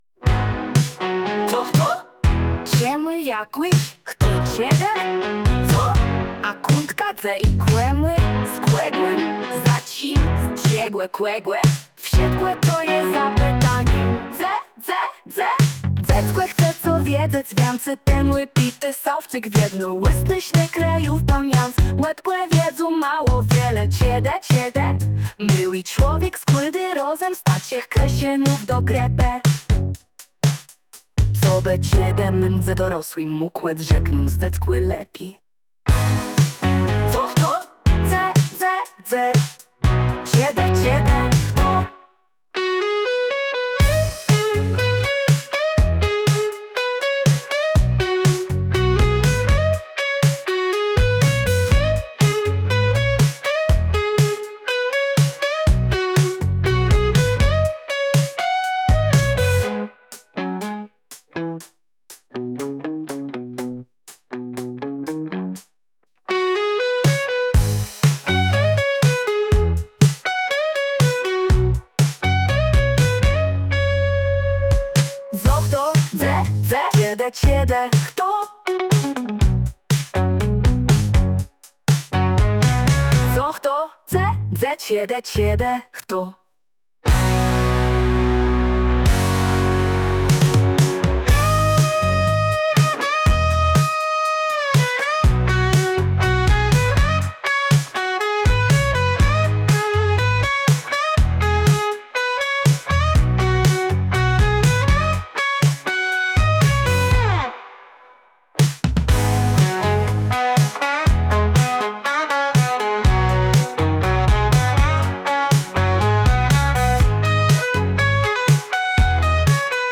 Podkład muzyczny tytuł